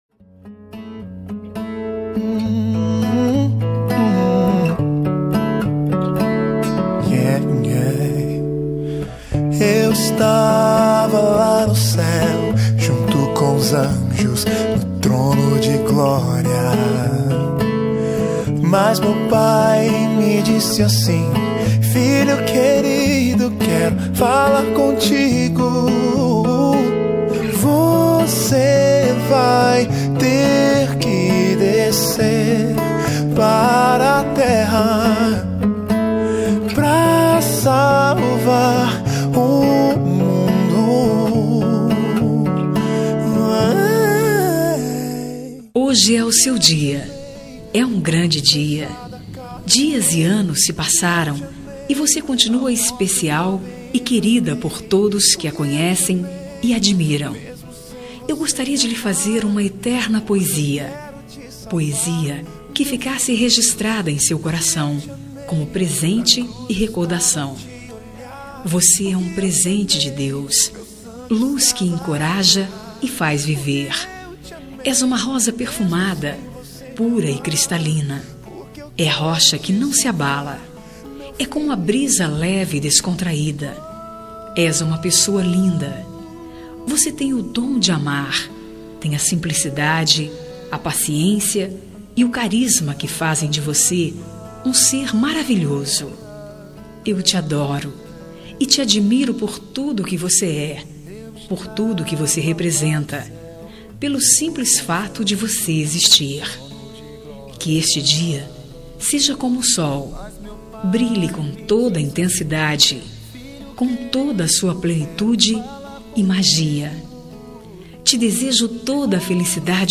Aniversário de Irmã Gospel – Voz Feminina – Cód: 6206